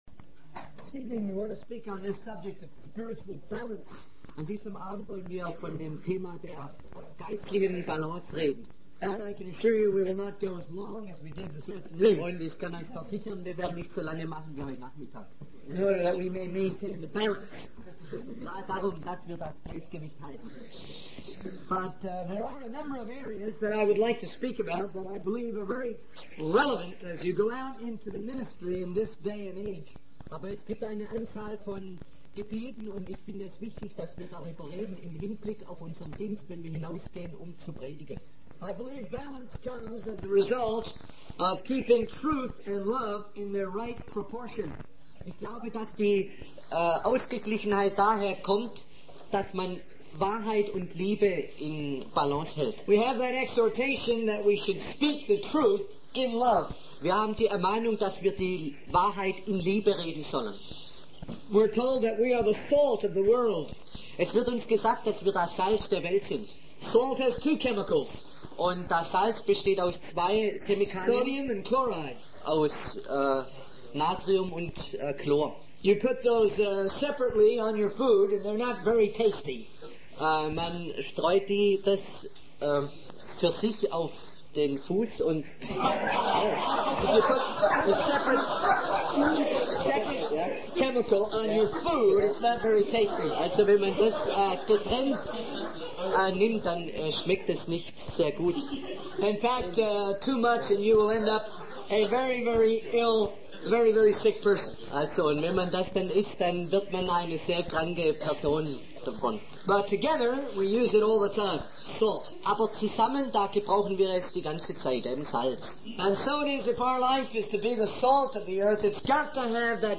In this sermon, the preacher discusses the tendency of religious Christians to fall into extremism and deviate from God's word. He emphasizes the need for young people to fully commit their lives to Christ and warns about the rise of false teachers and cults.